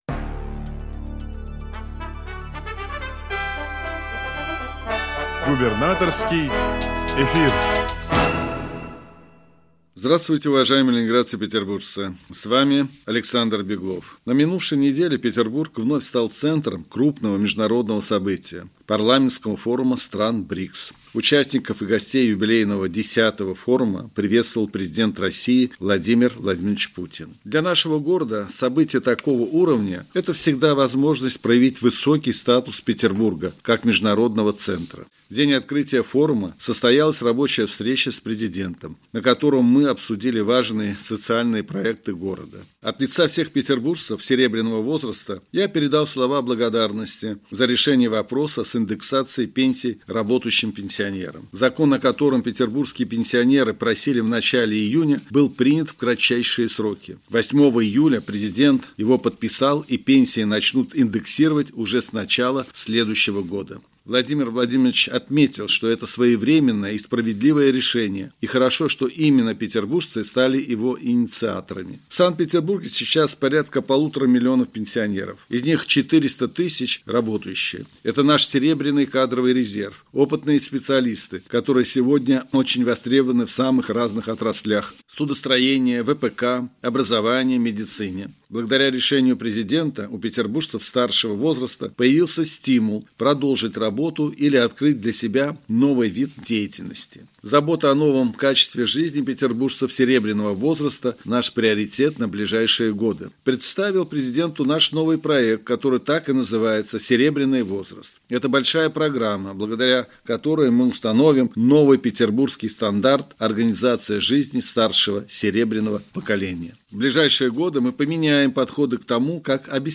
Радиообращение – 15 июля 2024 года